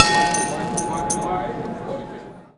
start auction.wav